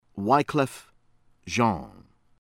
Henry, Ariel ah ree EL / on REE